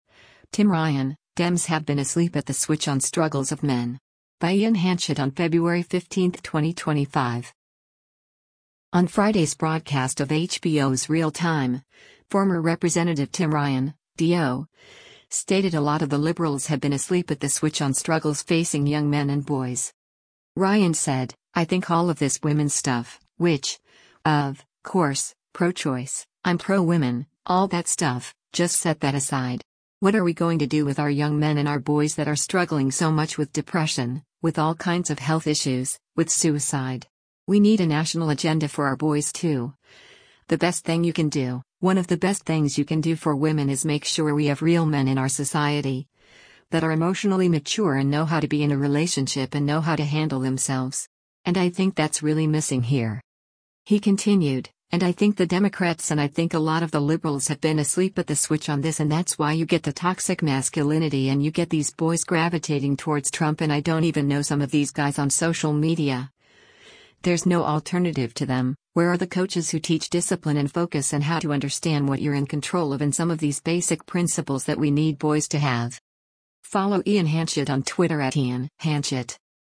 On Friday’s broadcast of HBO’s “Real Time,” former Rep. Tim Ryan (D-OH) stated “a lot of the liberals have been asleep at the switch on” struggles facing young men and boys.